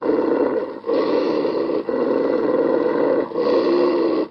Звуки рычания собаки
Рычащий пёс с рыком